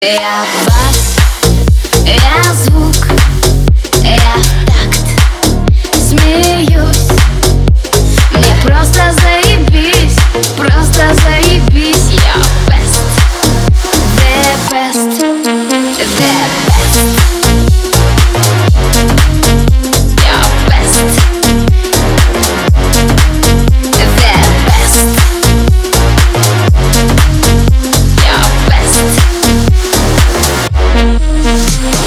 • Качество: 320, Stereo
женский вокал
зажигательные
Club House
future house